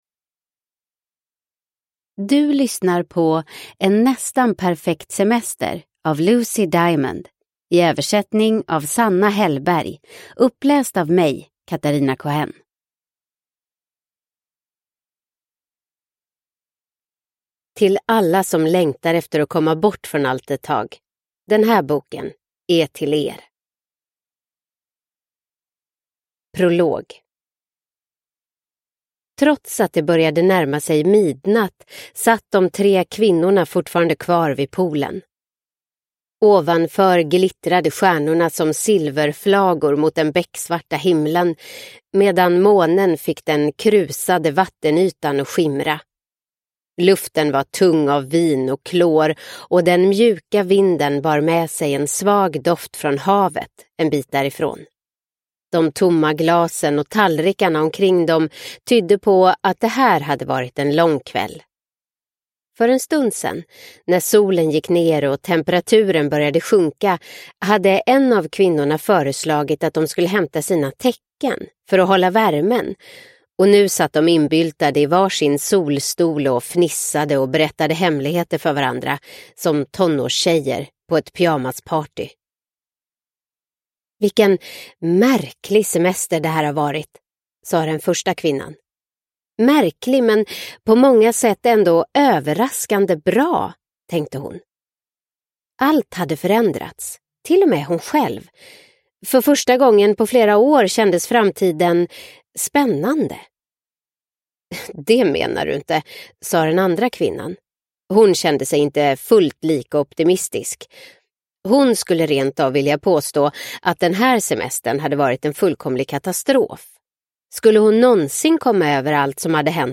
En nästan perfekt semester – Ljudbok – Laddas ner